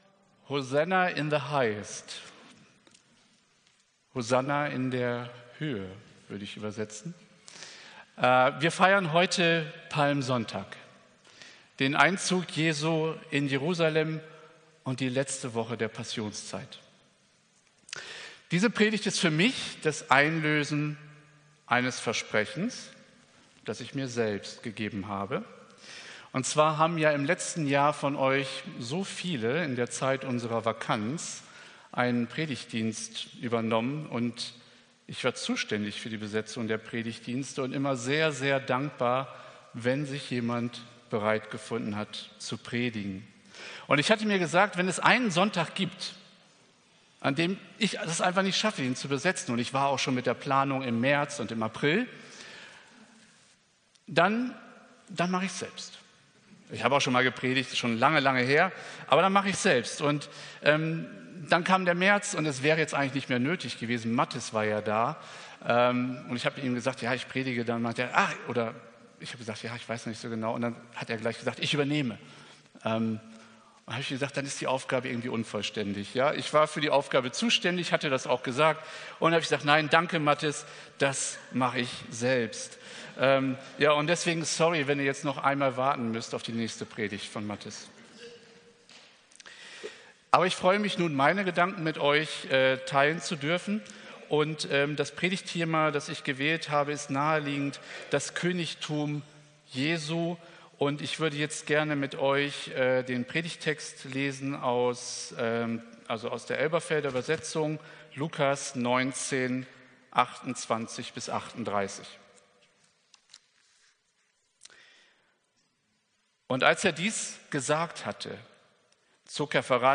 Predigt vom 24.03.2024